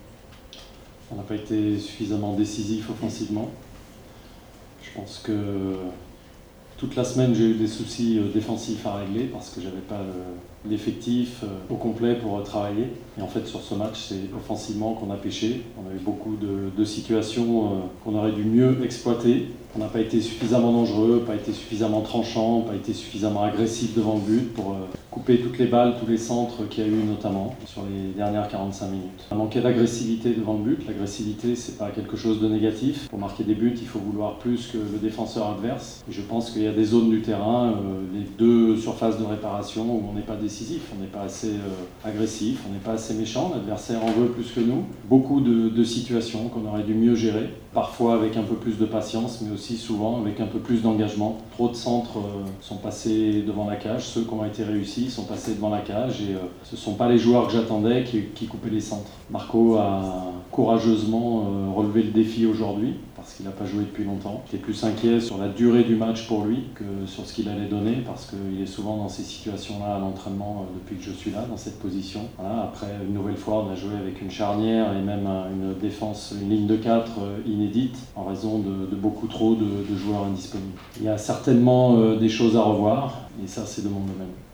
Conferenza stampa post-partita di Rémi Garde:
La conferenza stampa di fine partita è stata alquanto tesa ed il tecnico francese non ha verosimilmente gradito alcune delle domande poste dai giornalisti, a proposito delle prestazioni della squadra e delle sostituzioni.